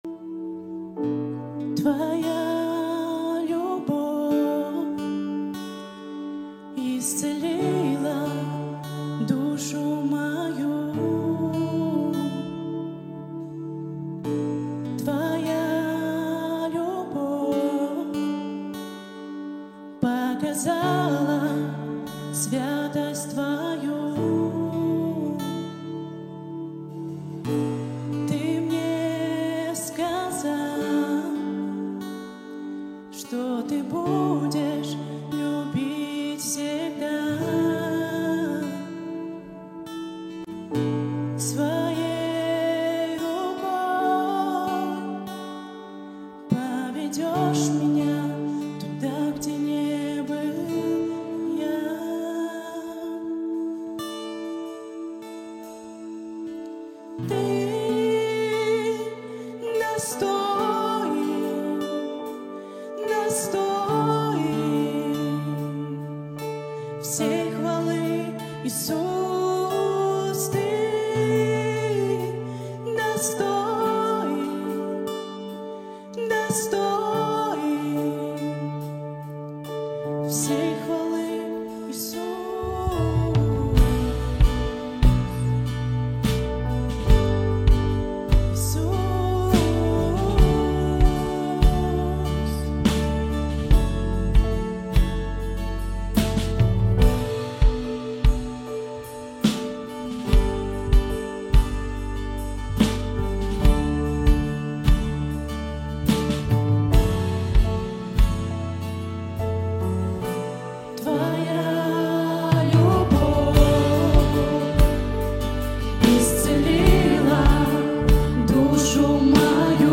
150 просмотров 182 прослушивания 1 скачиваний BPM: 130